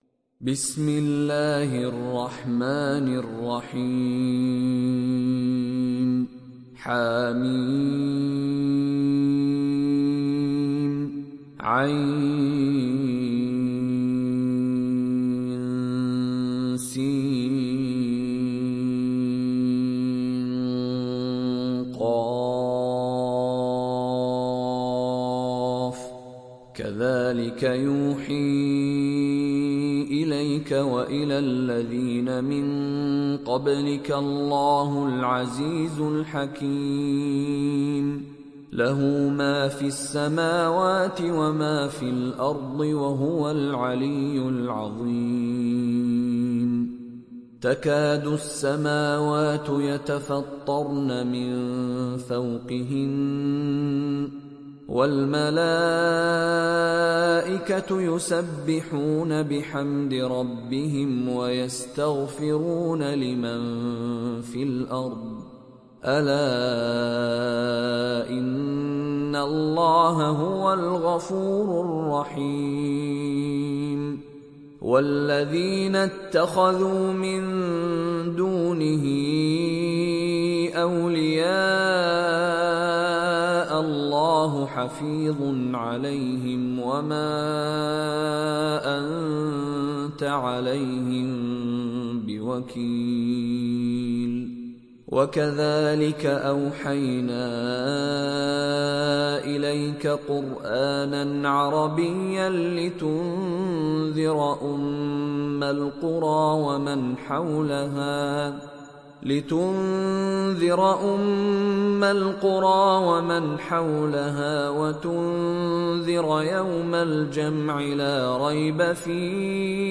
Sourate Ash Shura Télécharger mp3 Mishary Rashid Alafasy Riwayat Hafs an Assim, Téléchargez le Coran et écoutez les liens directs complets mp3